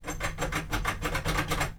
sfx_action_doorknob_04.wav